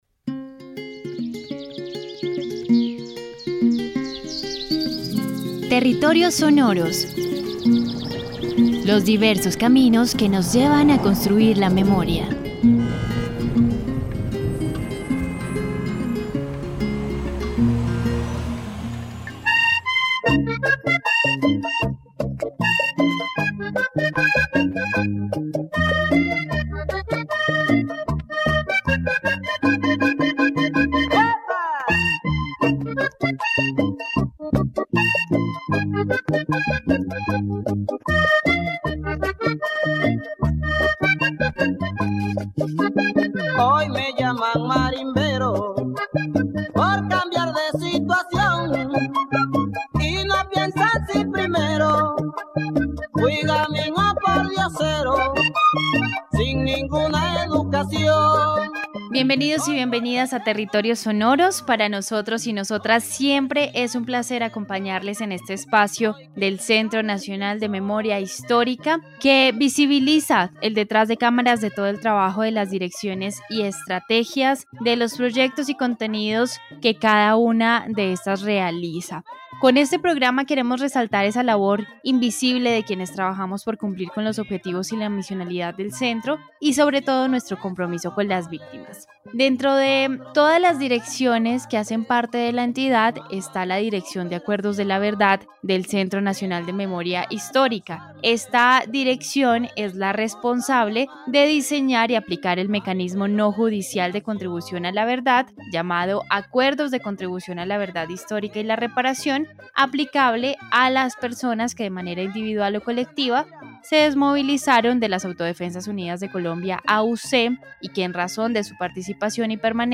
Charla sobre el Informe 11 sobre el origen y la actuación de las agrupaci